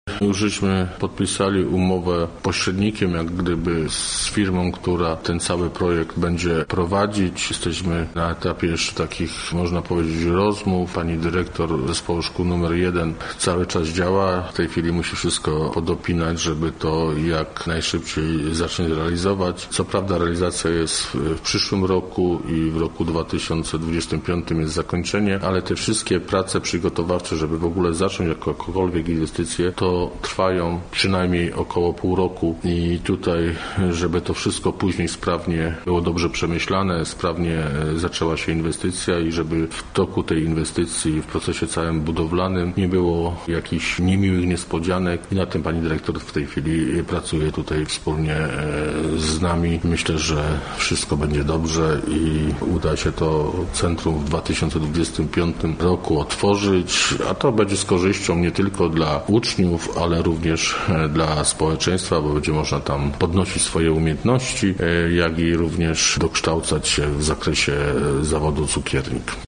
– mówił starosta, Marek Kieler.